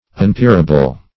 Unpeerable \Un*peer"a*ble\, a. Incapable of having a peer, or equal.